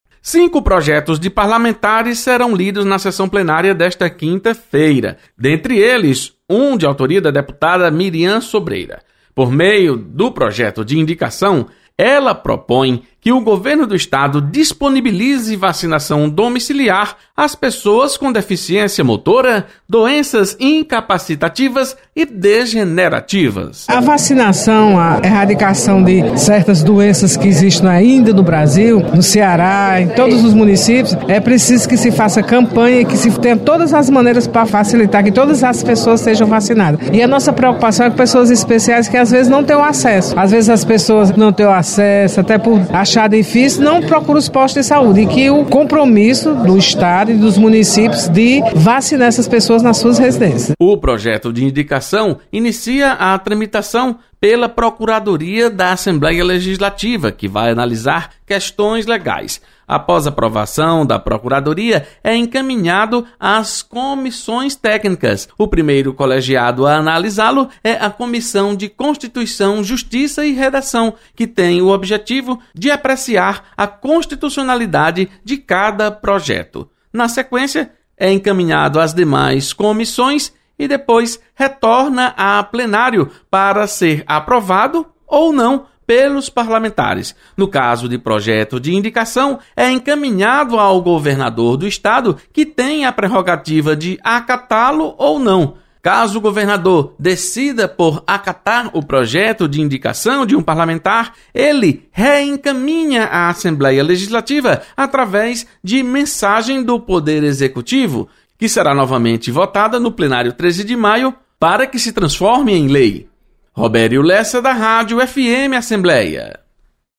Deputada Mirian Sobreira sugere vacinação em domicílio às pessoas com deficiência motora  e doenças degenerativas. Repórter